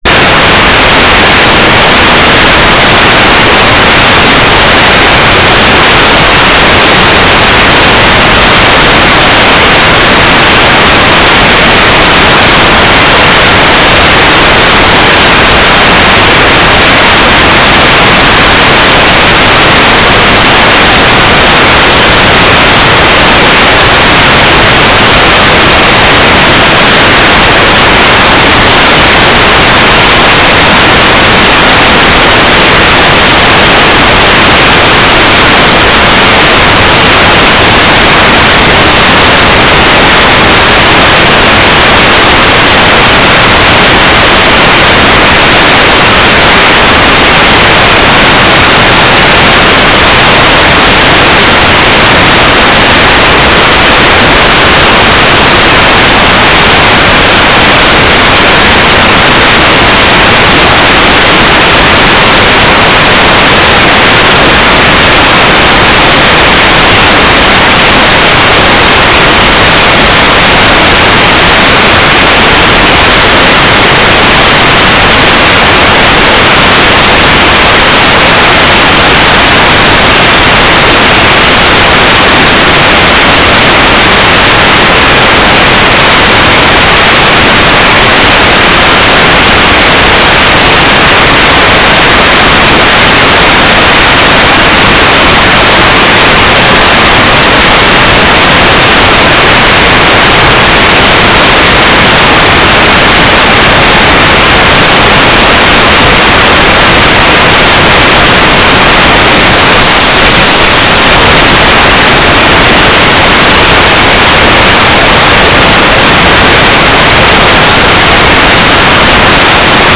"transmitter_description": "Mode U - GMSK 4k8 AX.25 TLM",